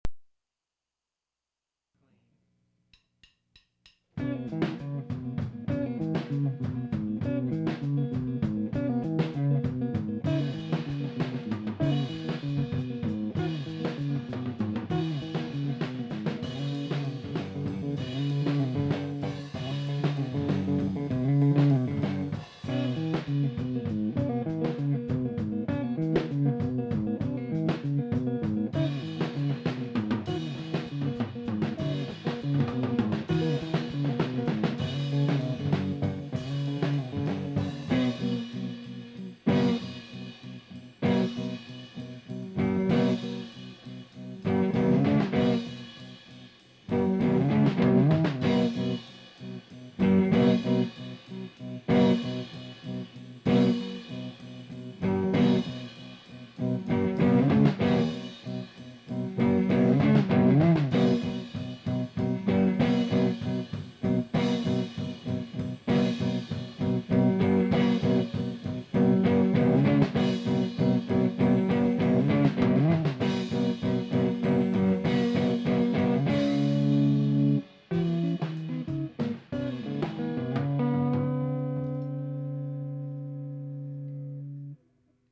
vox coming soon